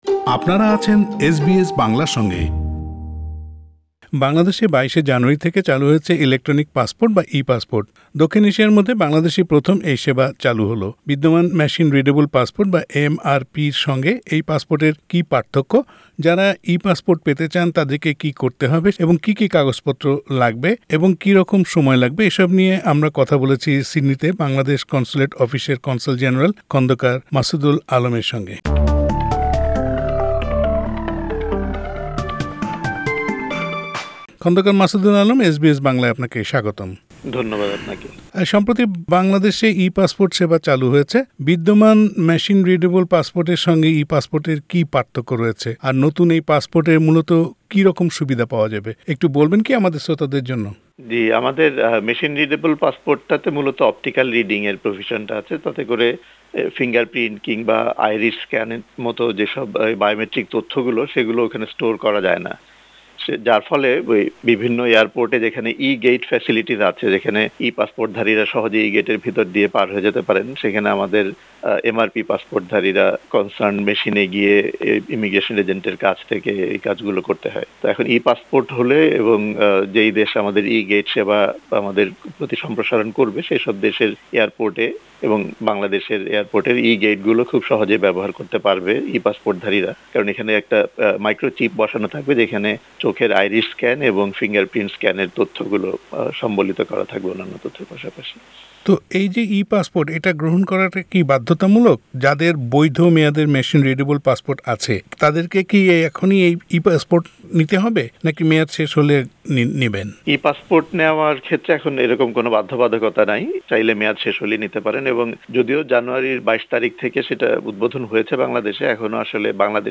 বিদ্যমান মেশিন রিডেবল পাসপোর্ট বা এমআরপি-র সঙ্গে ই-পাসপোর্টের কী পার্থক্য, যারা ই-পাসপোর্ট পেতে চান তাদেরকে কী করতে হবে, সেজন্য কতো খরচ হবে, কাগজ-পত্র কী কী লাগবে এবং কী রকম সময় লাগবে এসব নিয়ে এসবিএস বাংলার সঙ্গে কথা বলেছেন সিডনিতে বাংলাদেশ কনসুলেট অফিসের কনসাল জেনারেল খন্দকার মাসুদুল আলম।